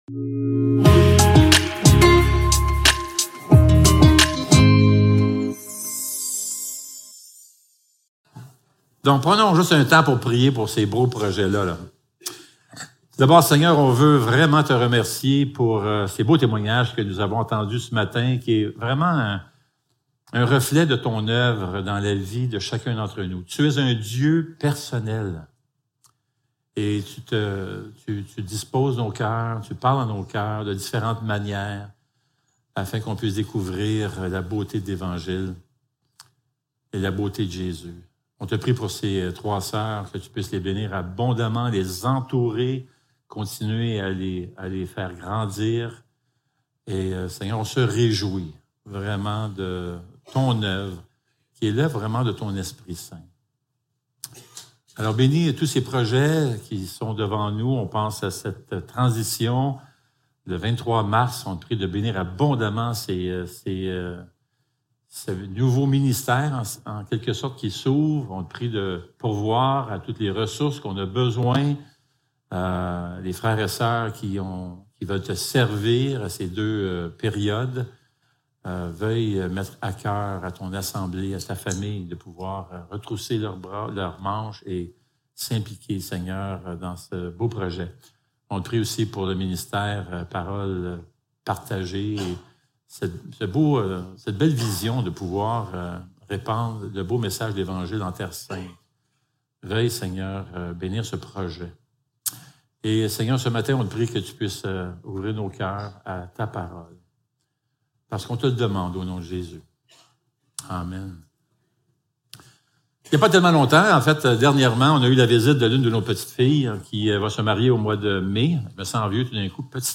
1 Jean 3.1-10 Service Type: Célébration dimanche matin Description